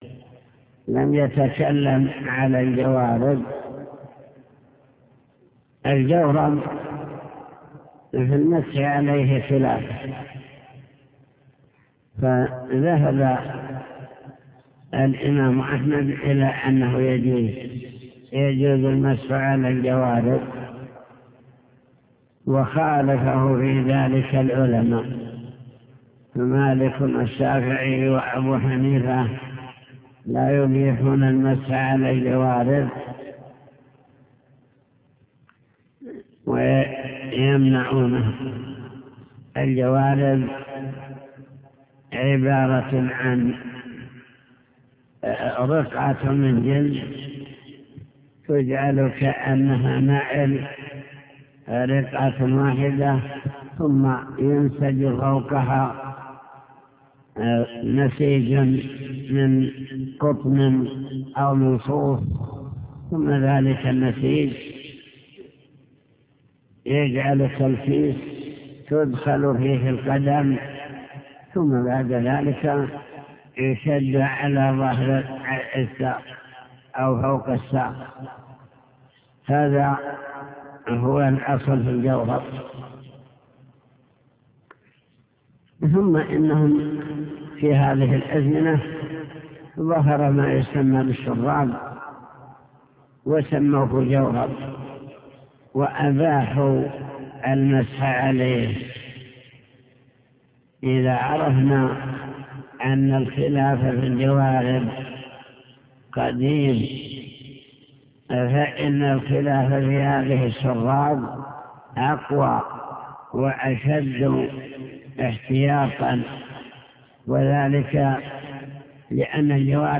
المكتبة الصوتية  تسجيلات - كتب  شرح كتاب دليل الطالب لنيل المطالب كتاب الطهارة المسح على الخفين